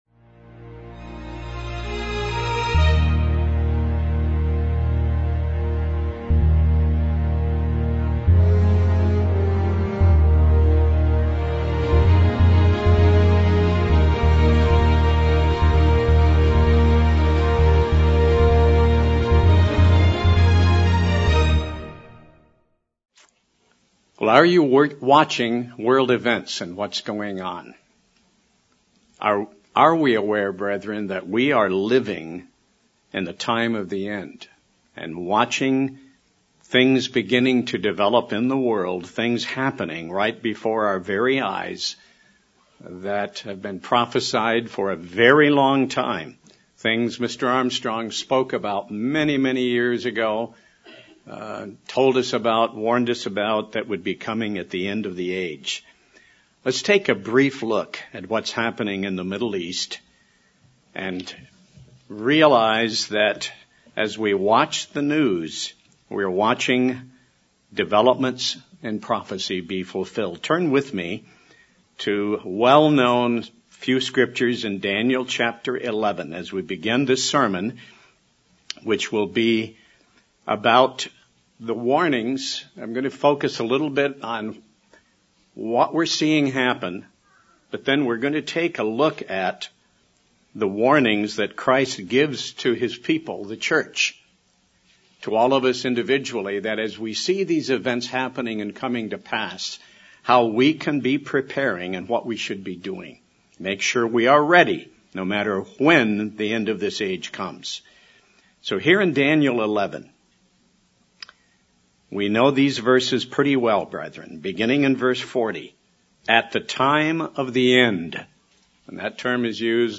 Prophetic Events and Christ's Warning to His People | United Church of God